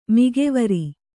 ♪ migevari